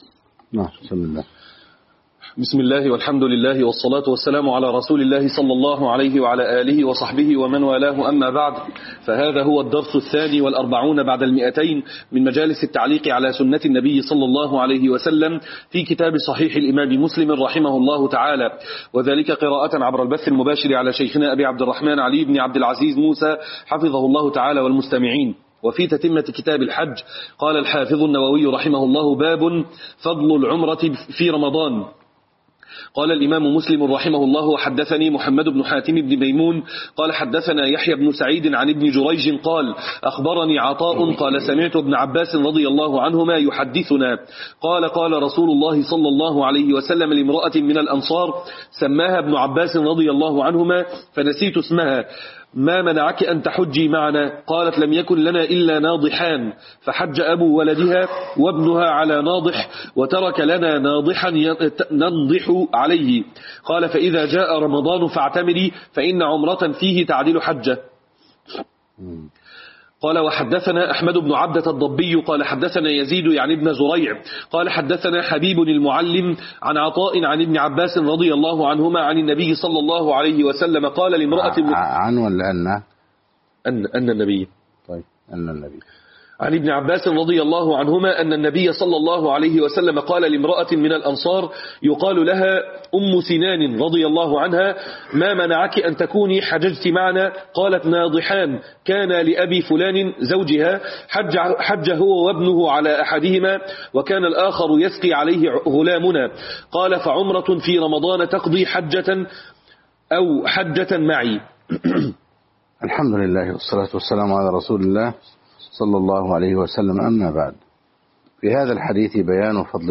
شرح صحيح مسلم الدرس 242